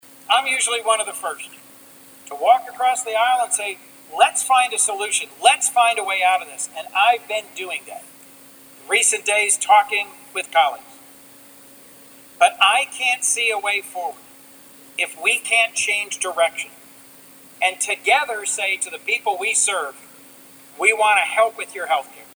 U.S. Senator Chris Coons spoke on the Senate floor after Democrats and Republicans failed to come to an agreement on government funding bills. Senator Coons focused his remarks on government funding and rising health care costs.